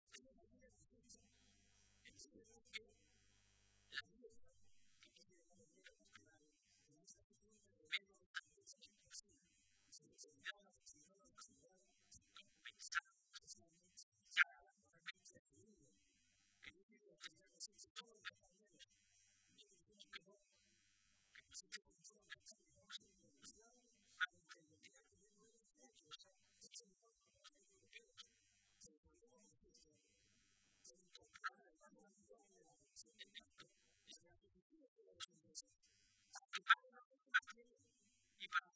Con los socialistas toledanos también compartió esta tradicional comida de navidad el eurodiputado del PSOE, Juan Fernando López Aguilar, quien en las palabras que pronunció en el turno de intervenciones pidió “encarar el futuro con ganas, con autocrítica pero sin derrotismo”.